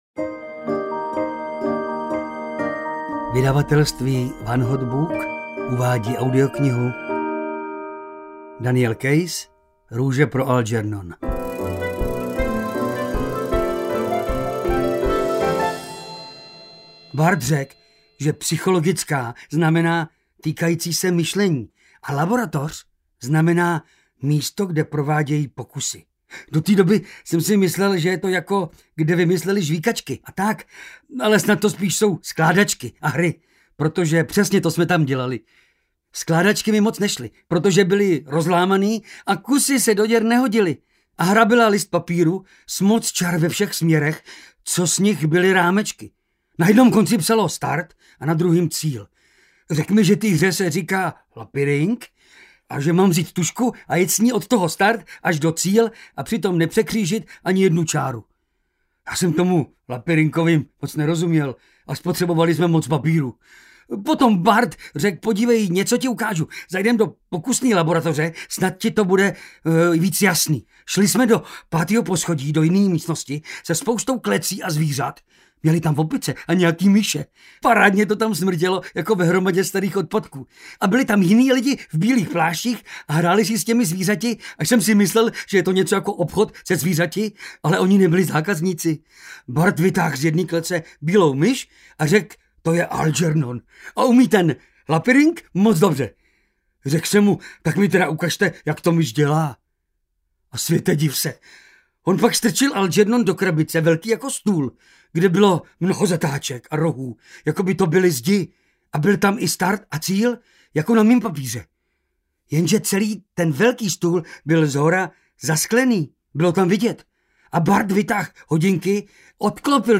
Růže pro Algernon audiokniha
Ukázka z knihy
• InterpretJan Potměšil